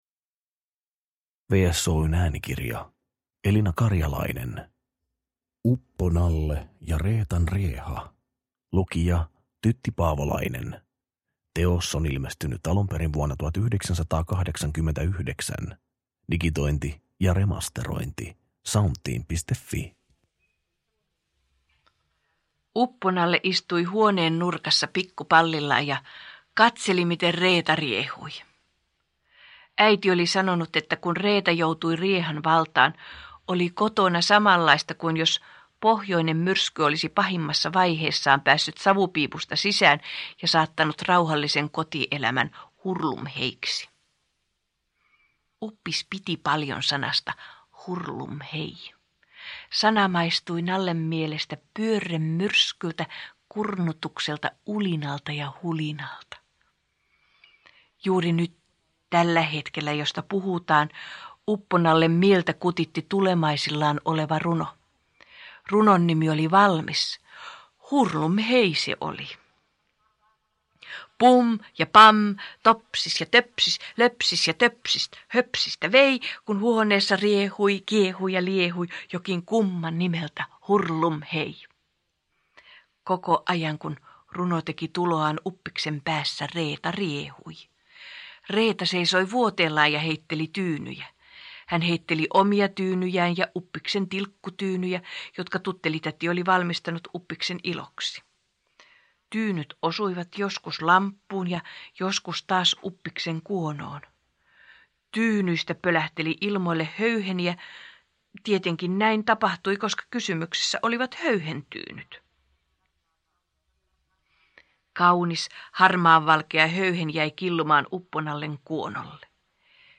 Uppo-Nalle ja Reetan rieha – Ljudbok – Laddas ner